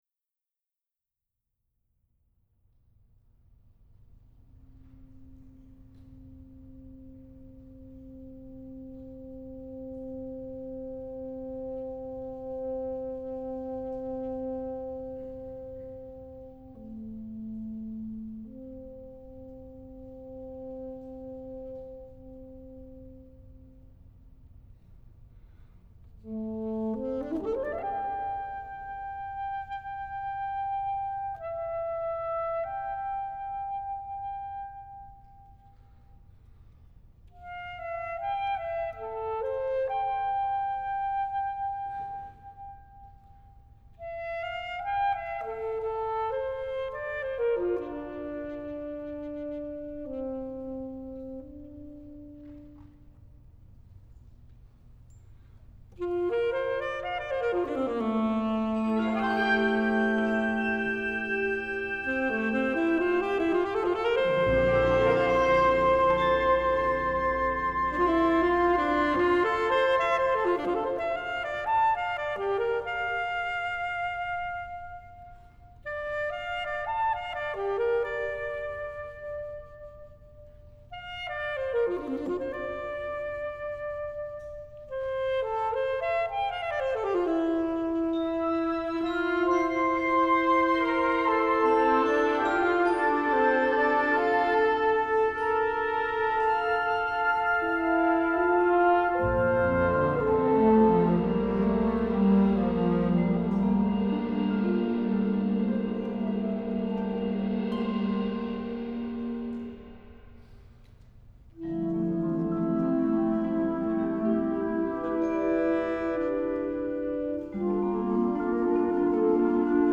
alto saxophone